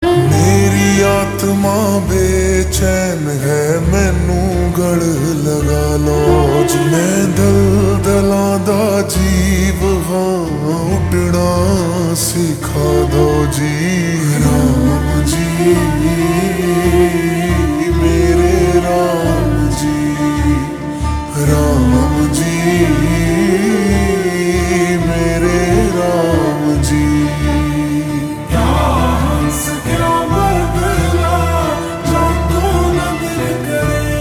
Punjabi Songs
Slow Reverb Version
• Simple and Lofi sound
• High-quality audio
• Crisp and clear sound